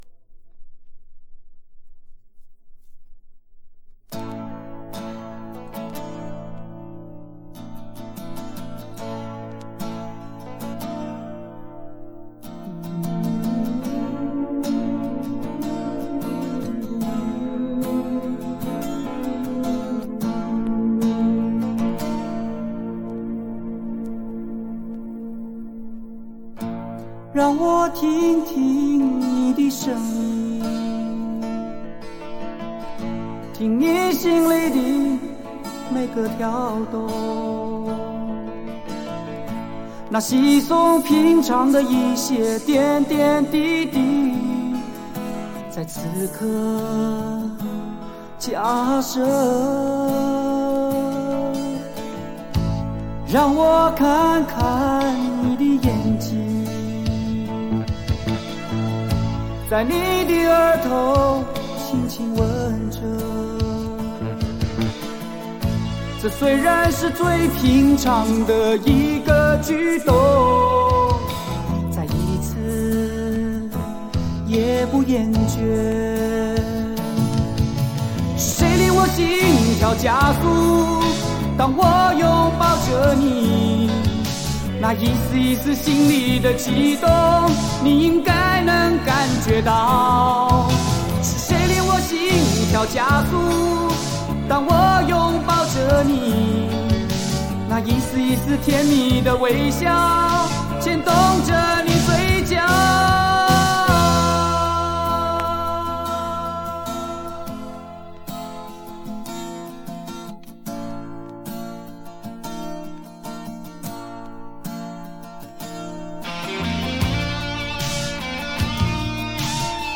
黑胶数字化